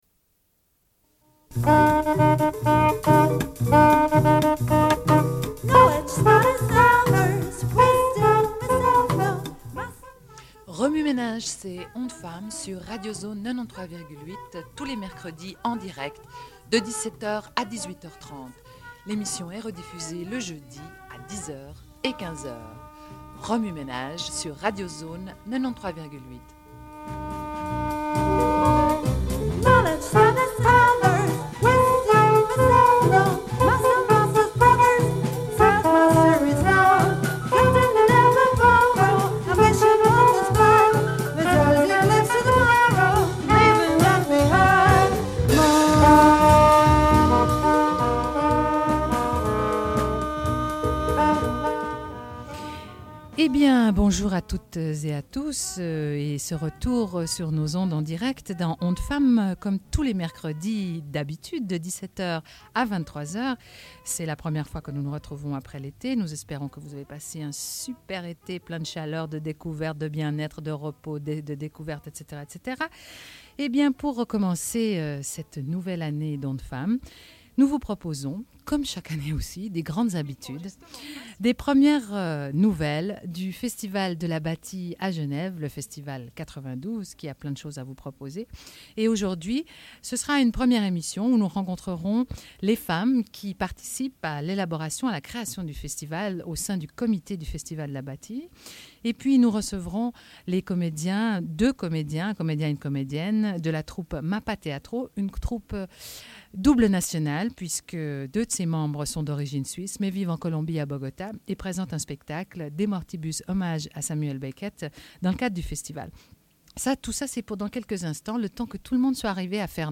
Une cassette audio, face A00:31:32
Sommaire de l'émission : au sujet du Festival de la Bâtie, édition 1992. Rencontre avec les femmes du comité du Festival. Rencontre avec deux comédien·nes de la troupe Mapap Teatro, au sujet de leur spectacle De Mortibus.